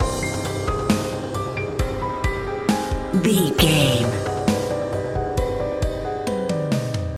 Aeolian/Minor
aggressive
chaotic
eerie
haunting
ominous
suspense
piano
synthesiser
drums